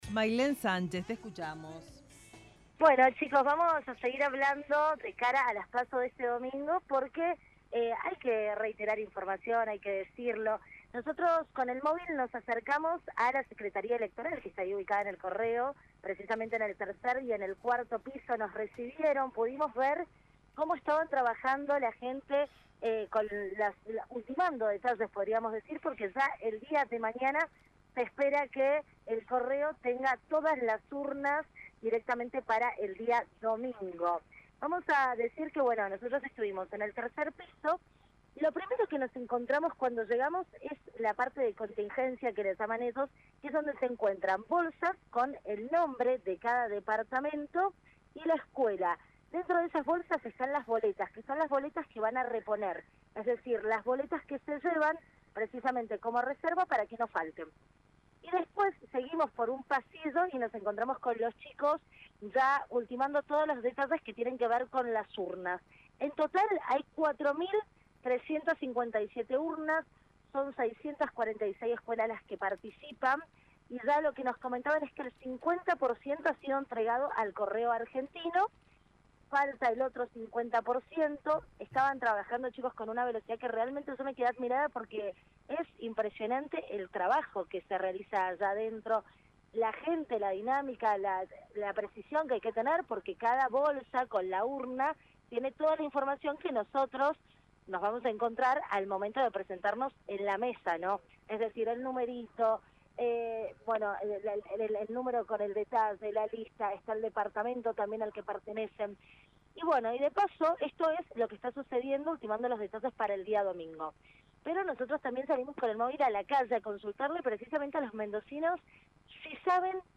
LVDiez - Radio de Cuyo - Móvil de LVDiez desde Secretaría Electoral Mendoza